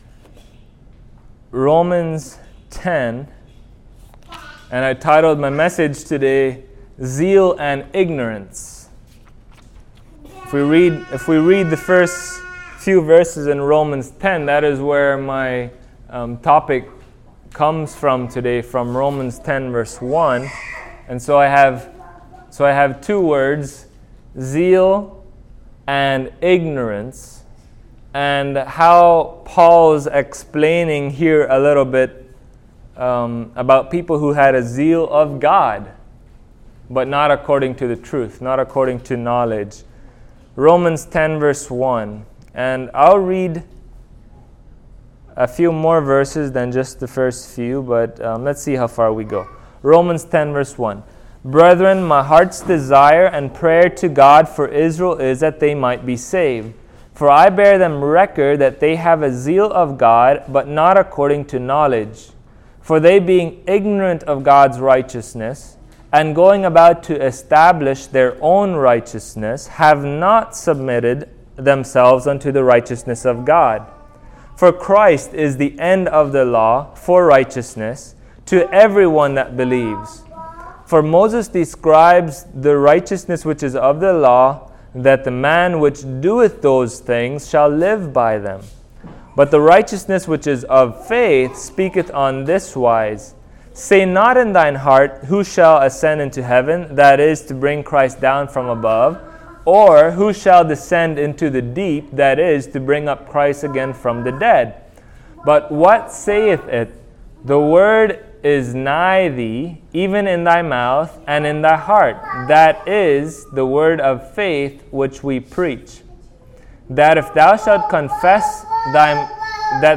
Passage: Romans 10:1-11 Service Type: Sunday Morning